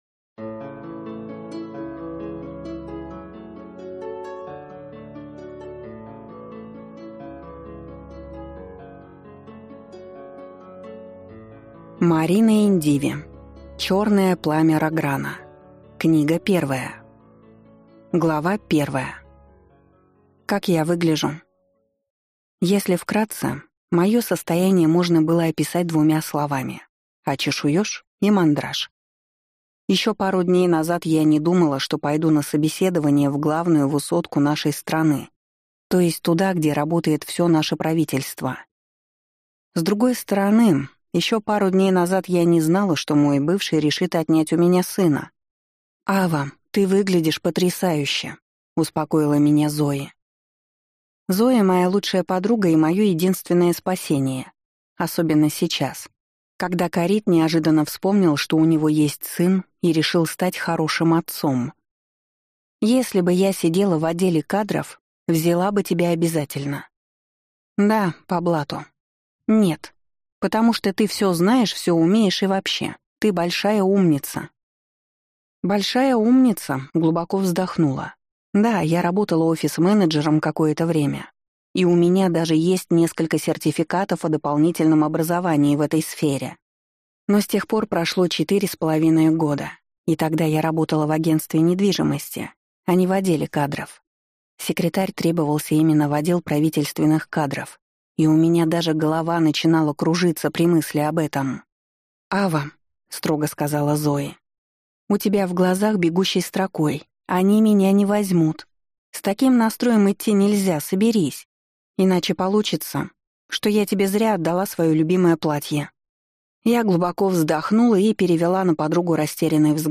Аудиокнига Черное пламя Раграна. Книга 1 | Библиотека аудиокниг